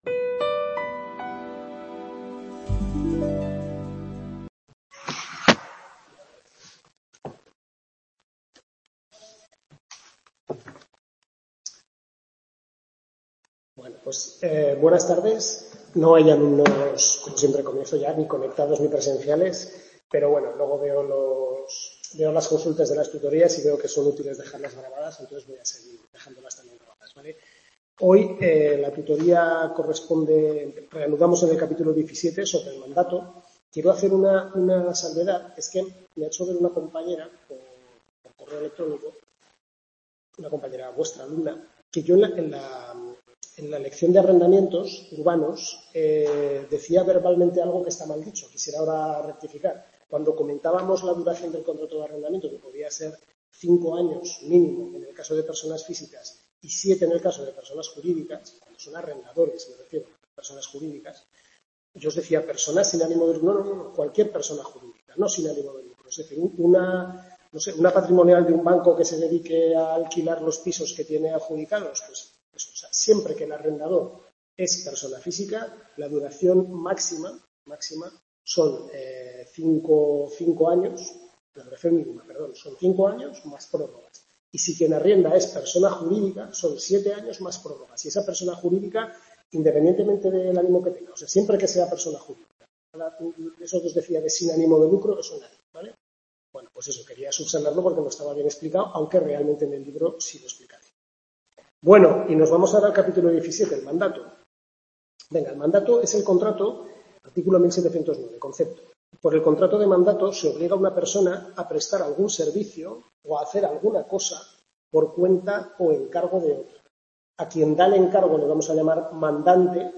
Tutoría 5/6 Derecho de Contratos, centro UNED-Calatayud, capítulos 17-19 del Manual del Profesor LAsarte